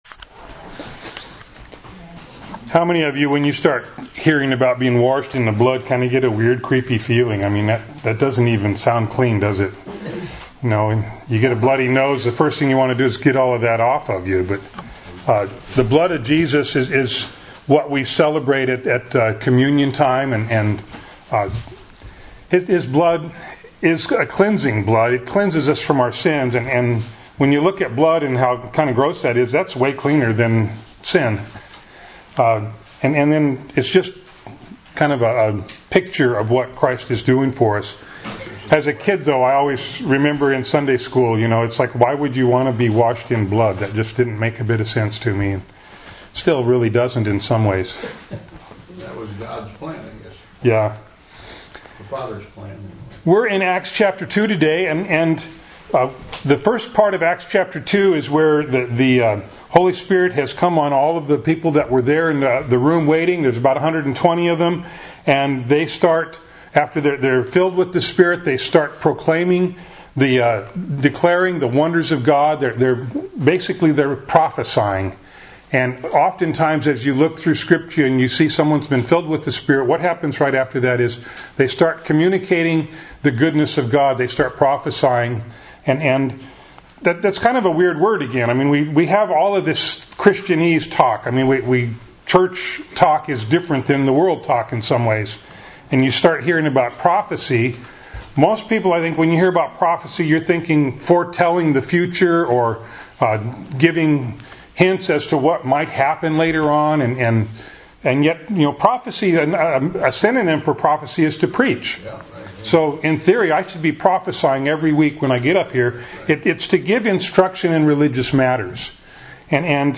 Acts 2:14-41 Service Type: Sunday Morning Bible Text